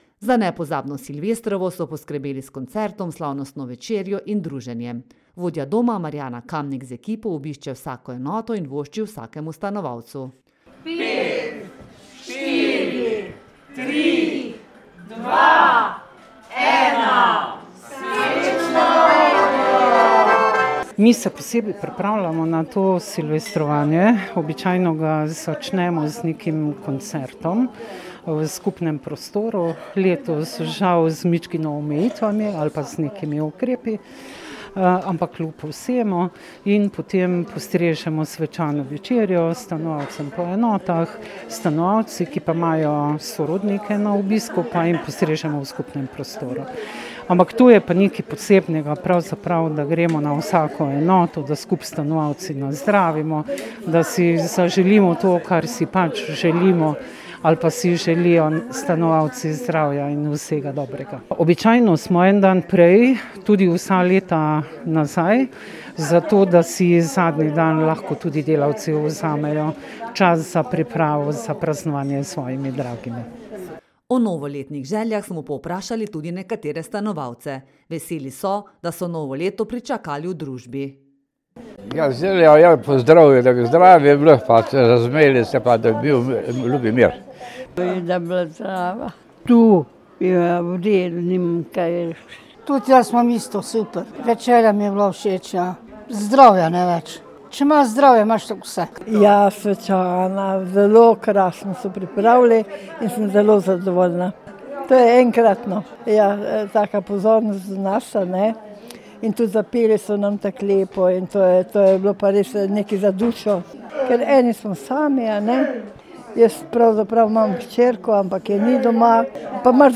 silvestrovanje.mp3